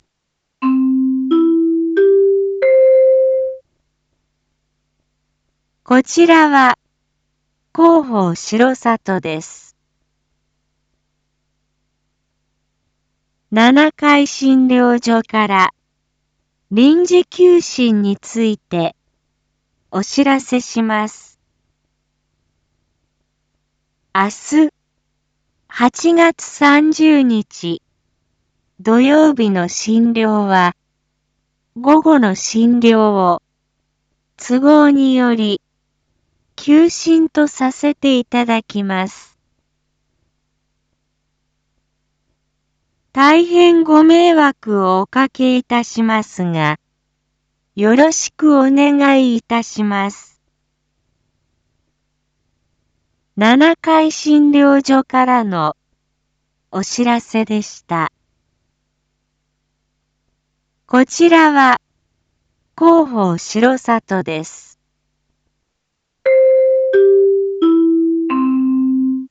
Back Home 一般放送情報 音声放送 再生 一般放送情報 登録日時：2025-08-29 19:02:57 タイトル：R7.8.30七会診療所医科臨時休診① インフォメーション：こちらは広報しろさとです。